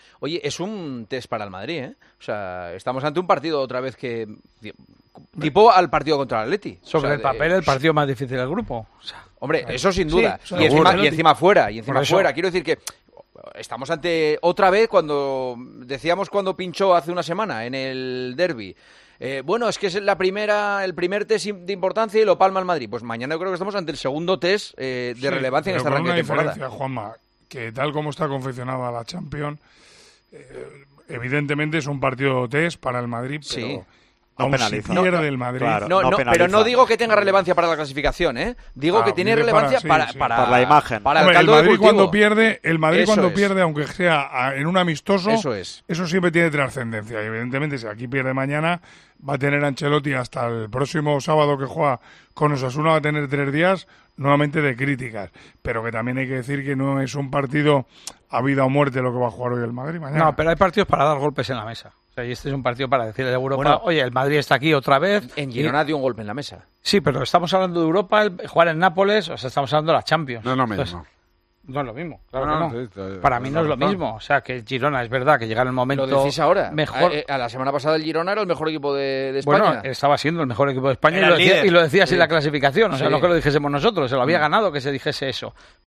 El director de El Partidazo de COPE habló del importante partido del Real Madrid en la segunda jornada de la Champions League de este martes.